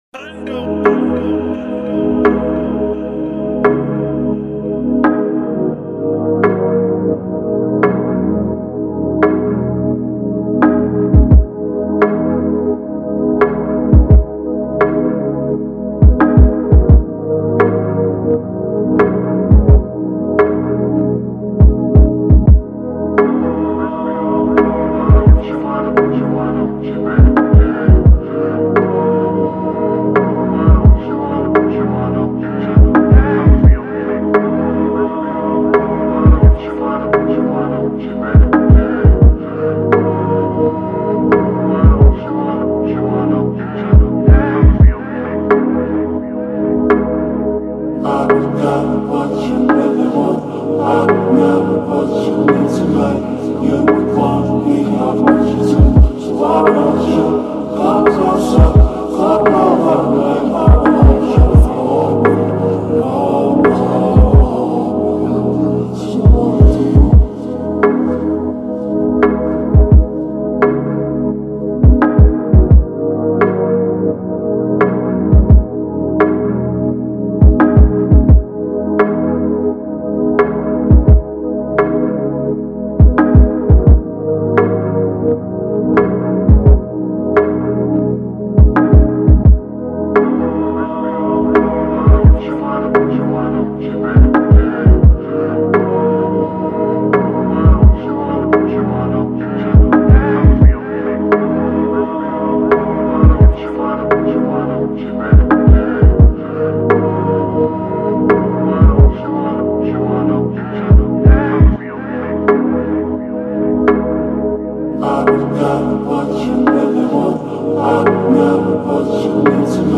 2024 in Official Instrumentals , Rap Instrumentals